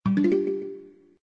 增加部分音效素材